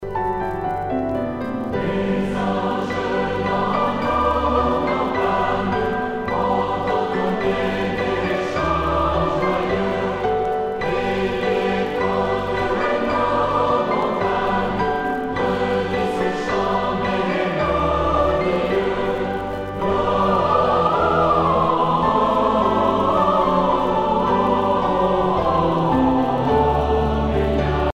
Noël
Genre strophique
Pièce musicale éditée